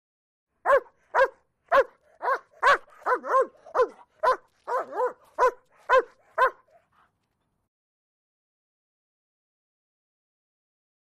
German Shepherd; Barks, Close Perspective.